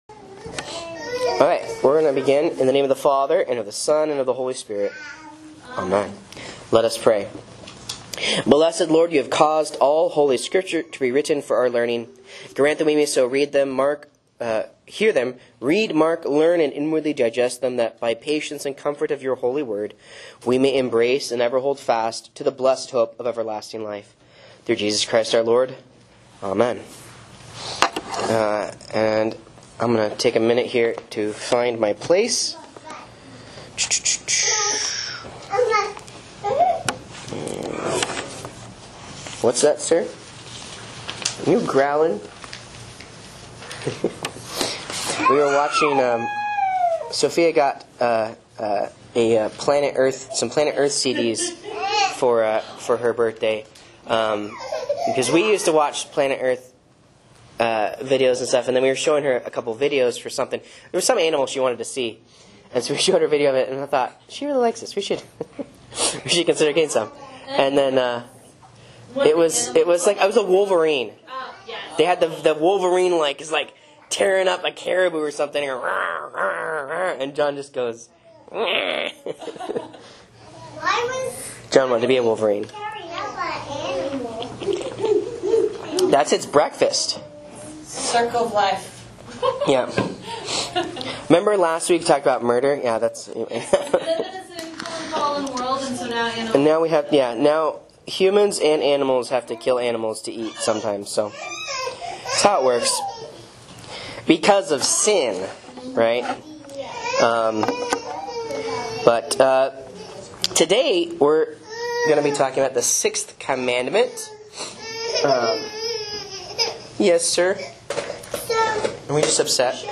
Sermons and Bible Classes